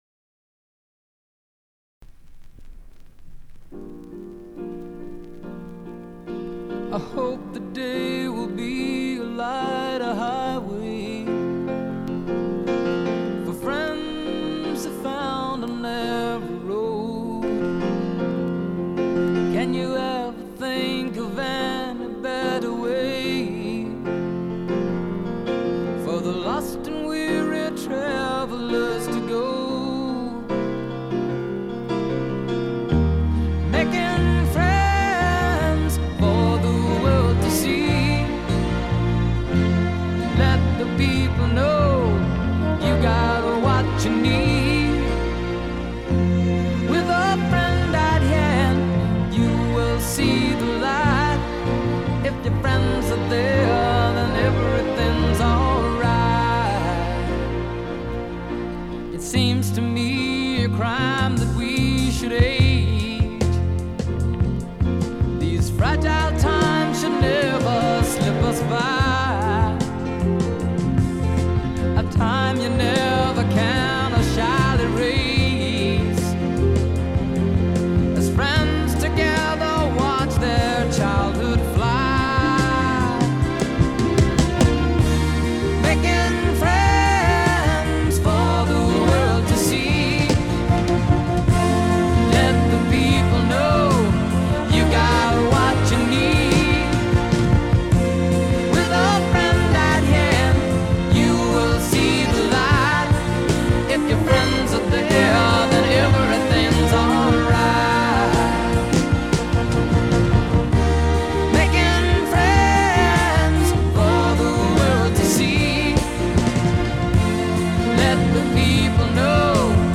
maudlin